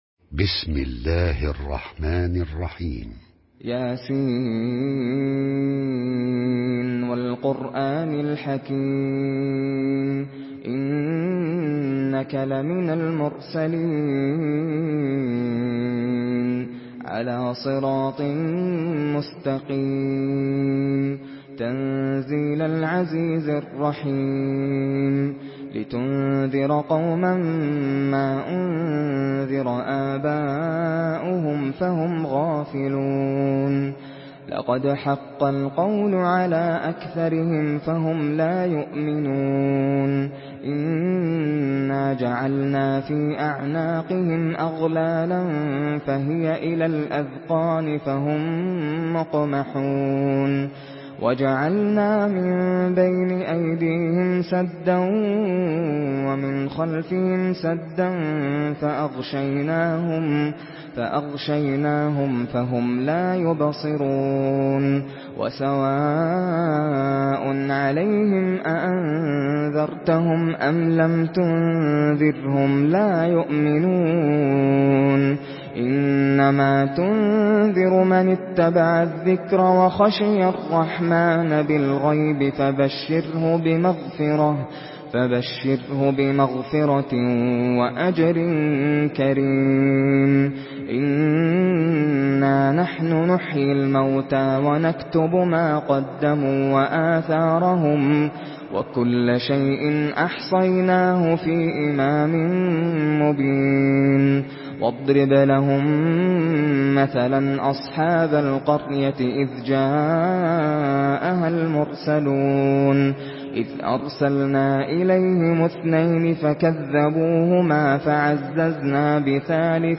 Surah Yasin MP3 in the Voice of Nasser Al Qatami in Hafs Narration
Surah Yasin MP3 by Nasser Al Qatami in Hafs An Asim narration.
Murattal